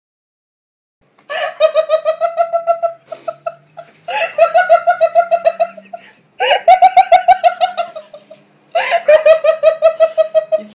Звуки женского смеха
Женщина смеется без перерыва